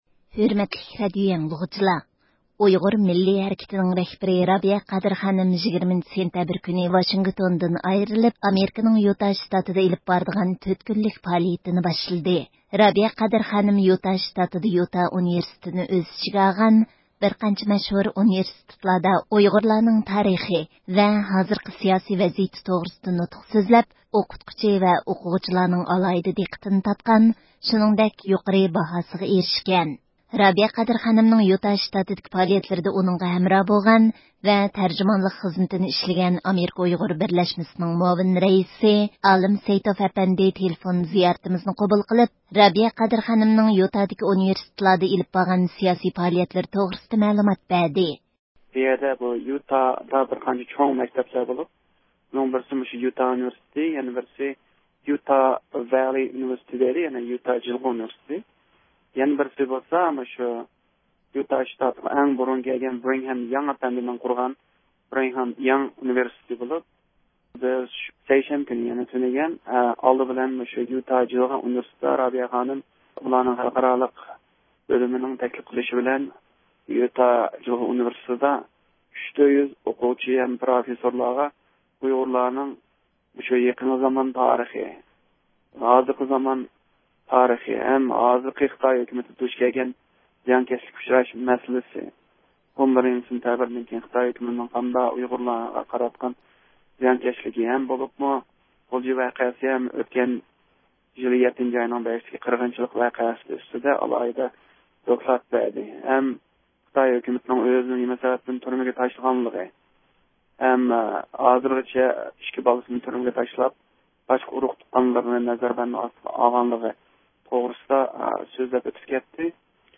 تېلېفون زىيارىتىمىزنى قوبۇل قىلىپ